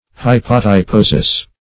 Search Result for " hypotyposis" : The Collaborative International Dictionary of English v.0.48: Hypotyposis \Hy`po*ty*po"sis\, n. [NL., fr. Gr.